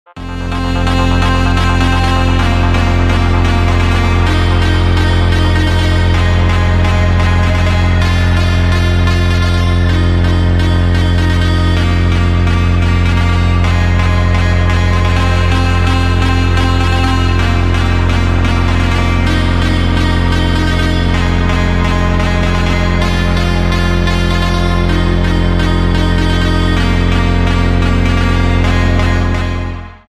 • Качество: 128, Stereo
Electronic
электронная музыка
без слов
басы
Trance